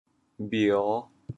How to say the words 瞄 in Teochew？